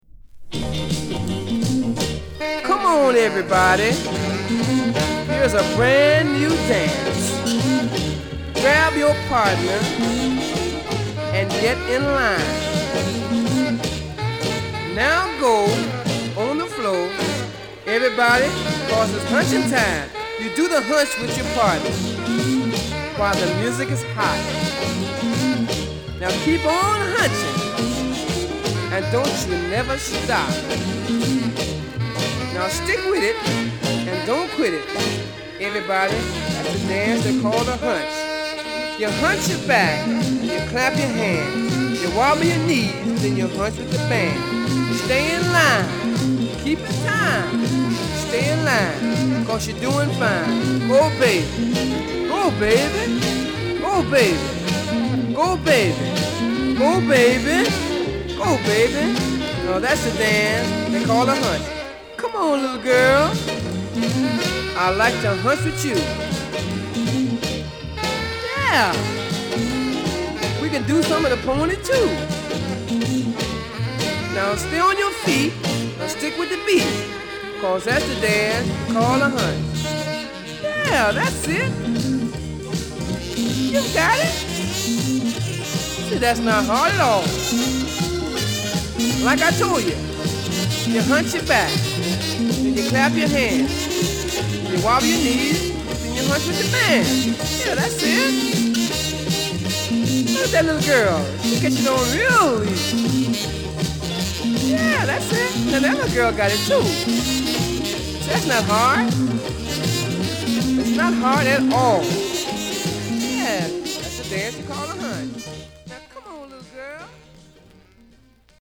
オリジナルよりピッチが早く、なおかつラフな仕上がりでこの人ならでは味わいがしっかりと音に反映されている。